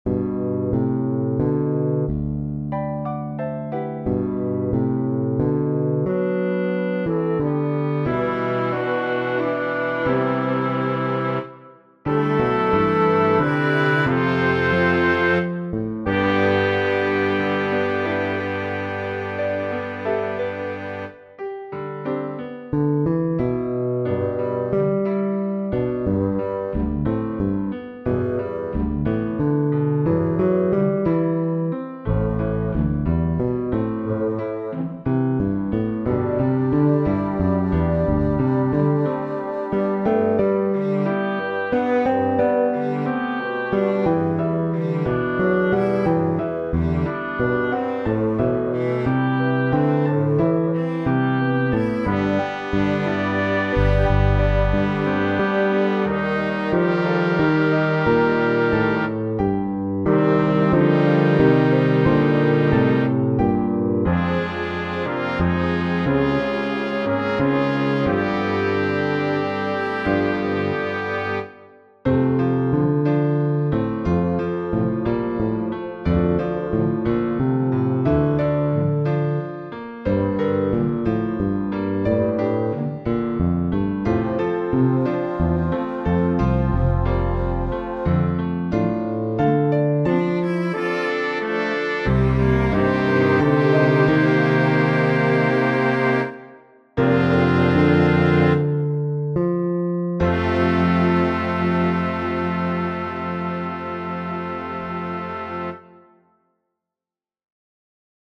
Electric Bass With 8-Piece Ensemble MS